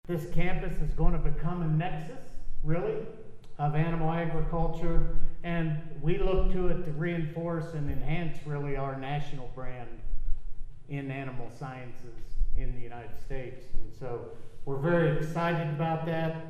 Friday’s ceremony was held inside the Stanley Stout Center, on the northern edge of the campus.